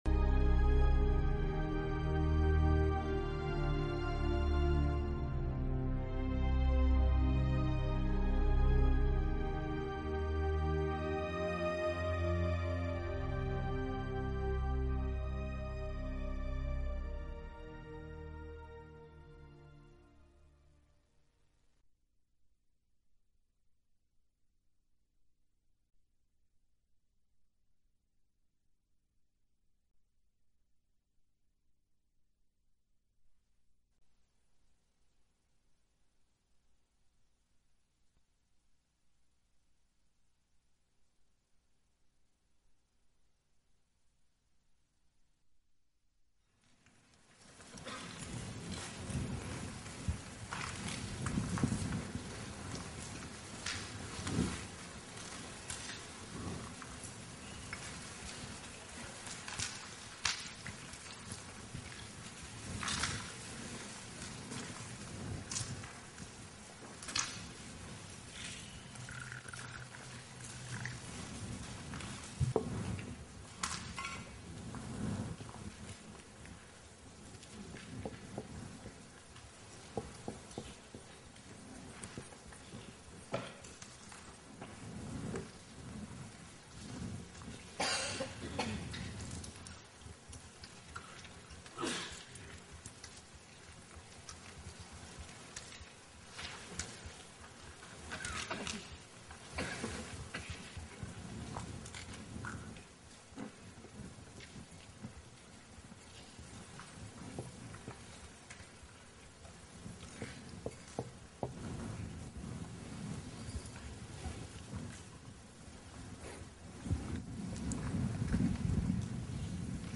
Nghe mp3 thuyết pháp Giới - Cần và Khổ dưới ánh sáng chánh niệm - Định đề 12 đến 15 do HT. Thích Nhất Hạnh giảng tại xóm Hạ, Làng Mai ngày 15 tháng 12 năm 2005